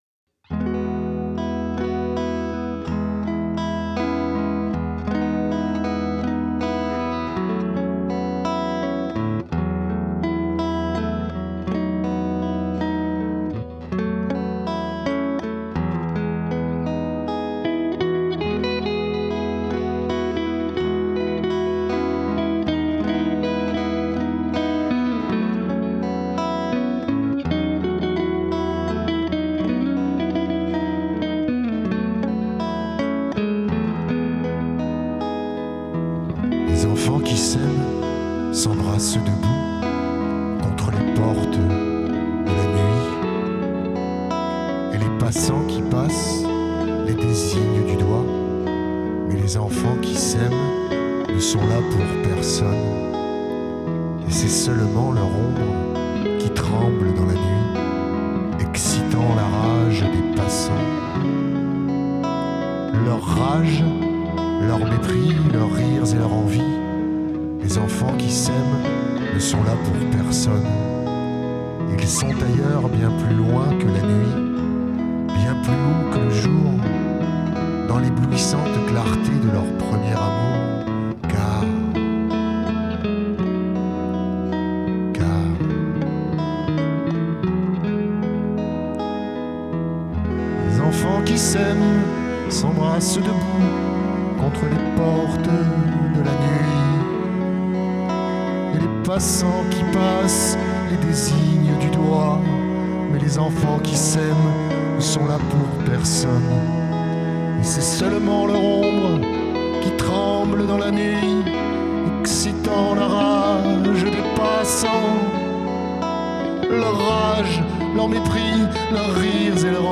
une véritable échappée poétique et musicale